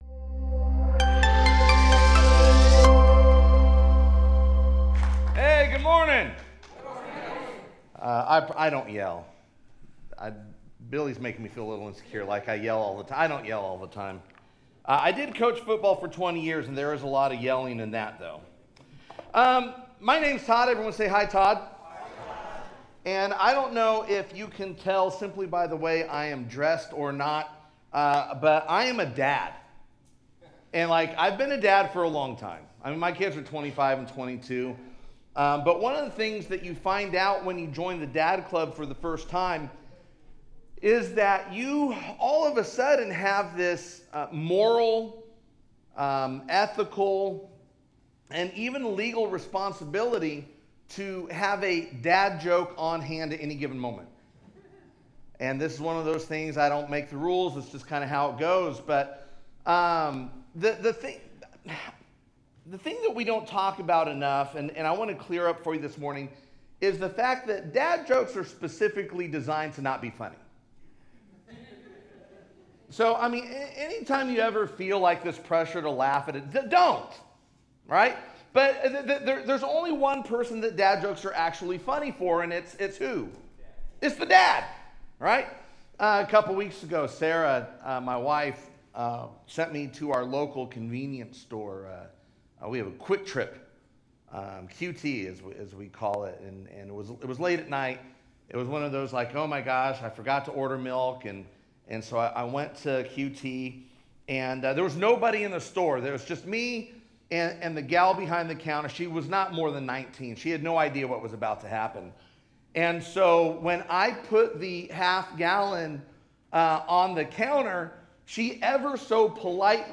Sermons | Innovation Church